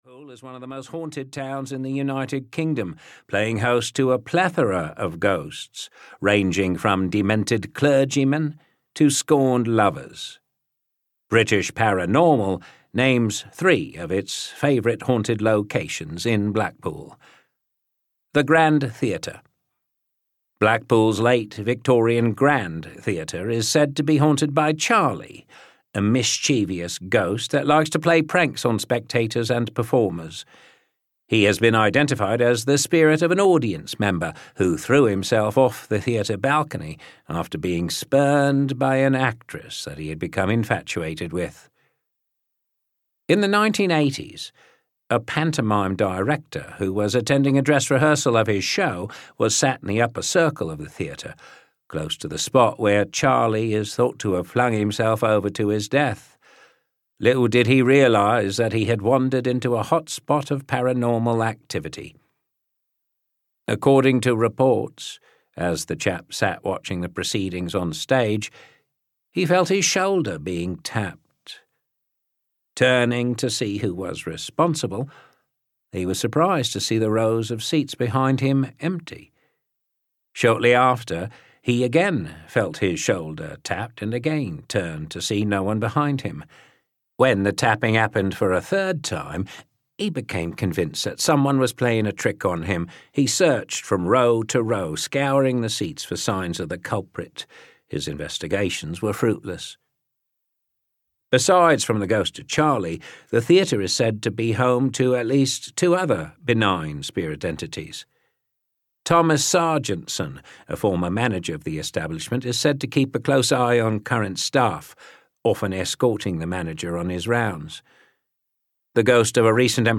England's Haunted Places (EN) audiokniha
Ukázka z knihy